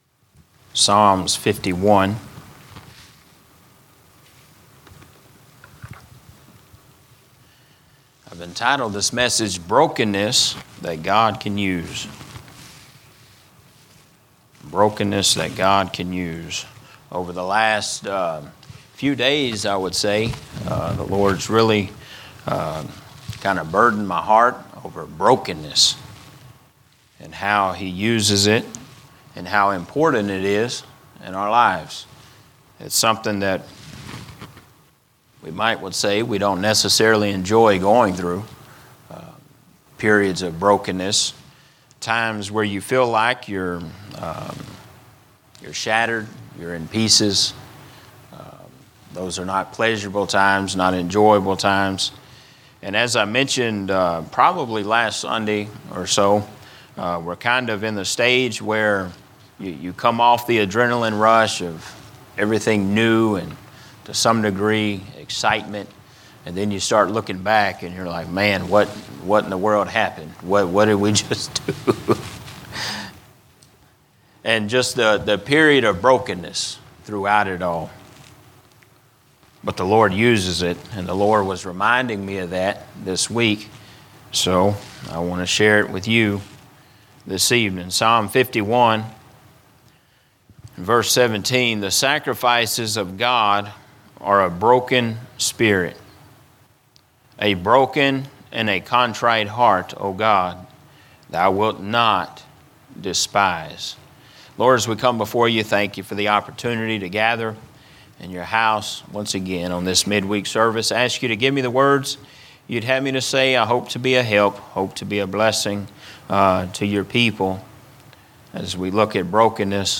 Sermons not part of a specific series